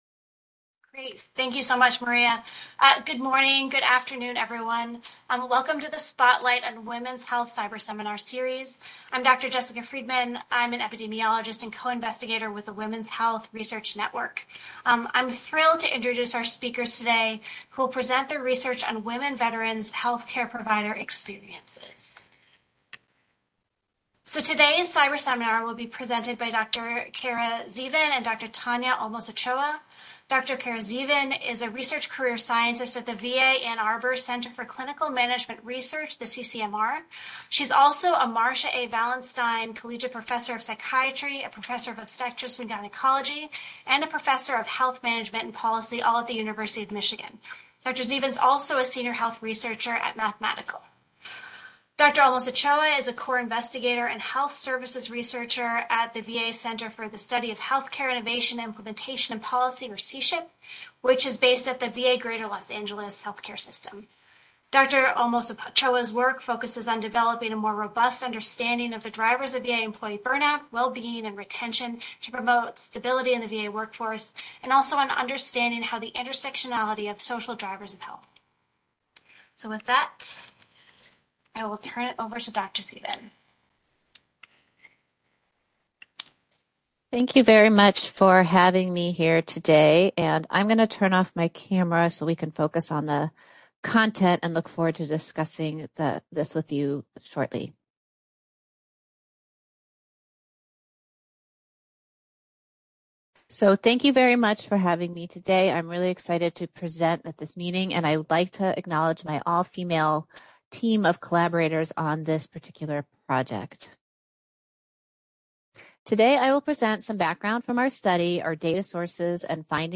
Description: This seminar will review findings from two studies that address employee engagement, well-being, and burnout among multiple VA clinicians and non-clinicians in Primary Care and Mental Health. One examines gender differences in Mental Health Provider employee experiences using mixed methods (quantitative data and interviews).